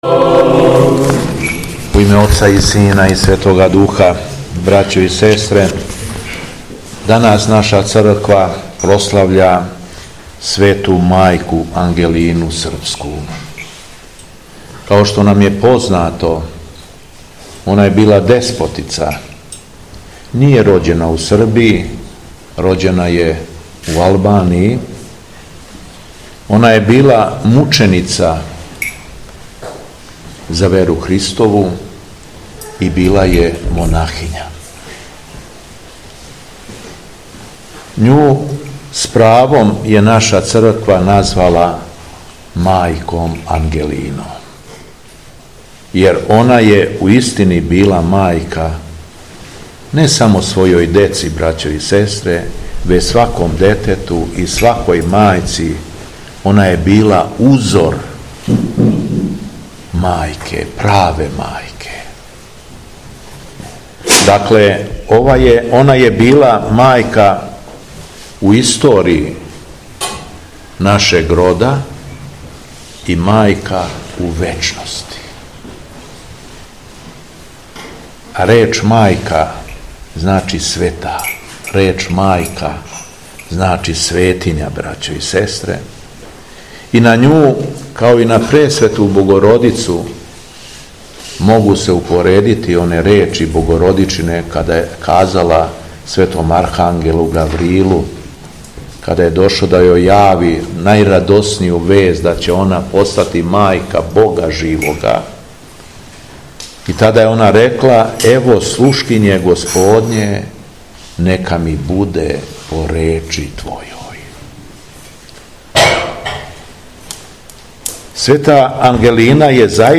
СВЕТА АРХИЈЕРЕЈСКА ЛИТУРГИЈА У ХРАМУ СВЕТОГ ТЕОДОРА ТИРОНА У ВЕЛИКИМ ПЧЕЛИЦАМА
Беседа Његовог Преосвештенства Епископа шумадијског г. Јована